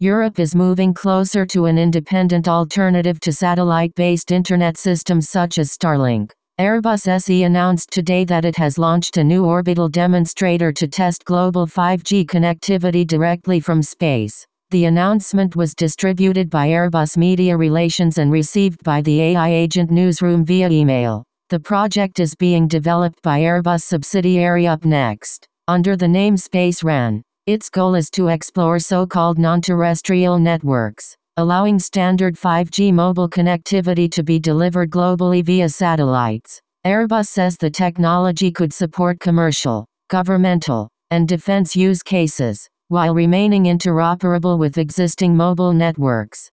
Listen to this AI-generated radio-style audio summary of this article, based on a press release distributed by Airbus Media Relations and received by the AI Agent Newsroom via email.
airbus-5g-radio-summary.wav